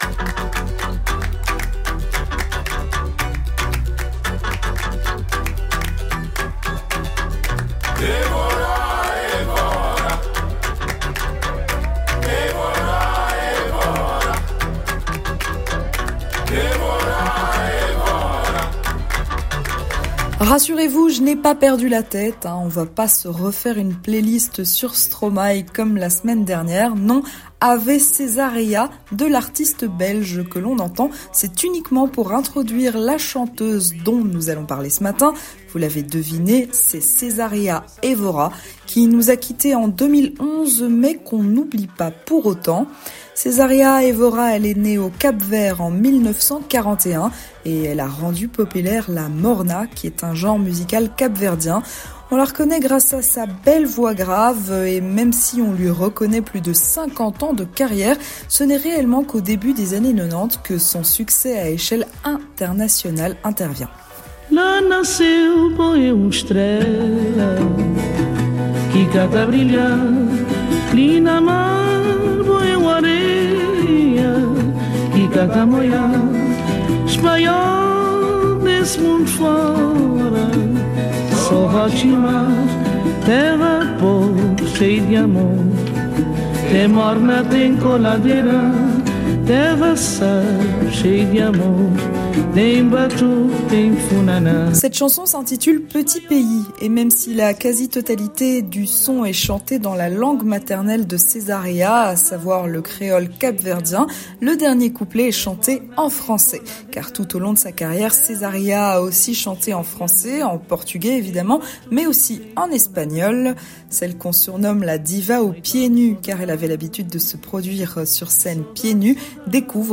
Une chronique imaginée